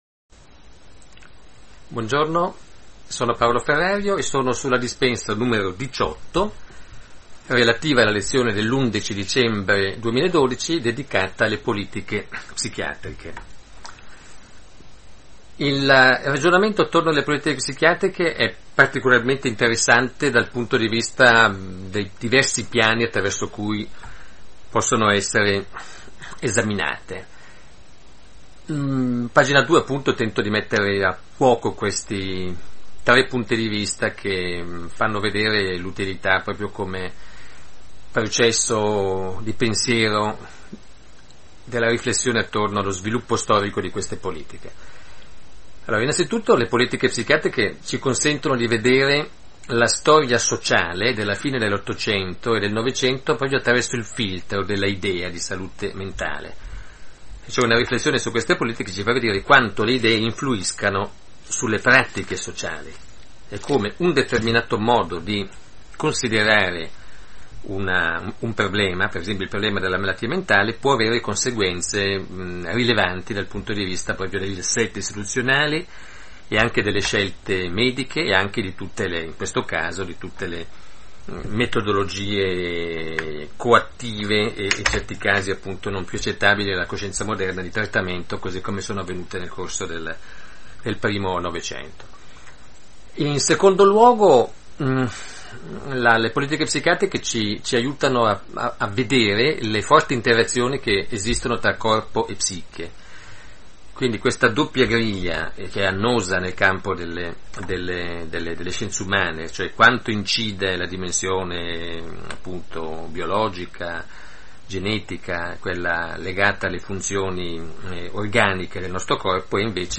Audio Lezione n. 2 politiche psichiatriche da 16 a 27